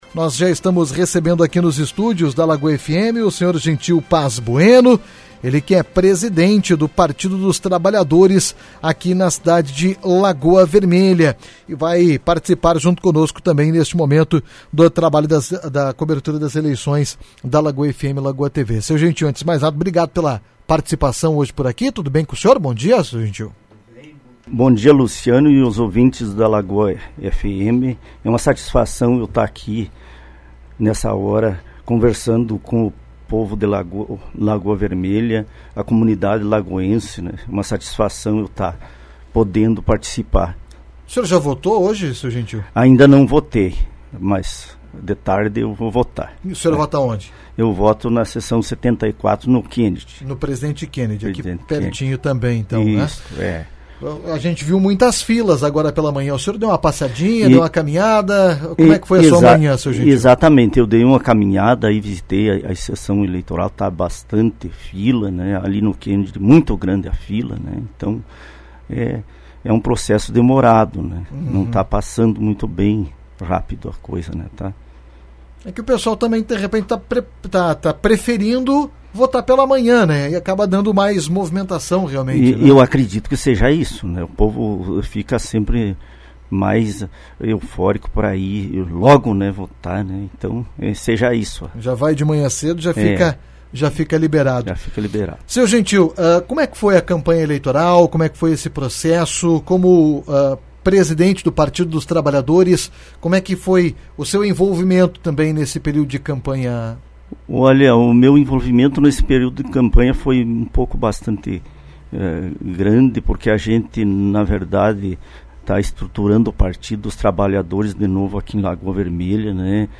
também esteve presente em nossos estúdios para falar sobre as eleições 2022. Destaca o número de pessoas nas filas e a demora para a votação. Falou também sobre seu envolvimento durante esse período de campanha. https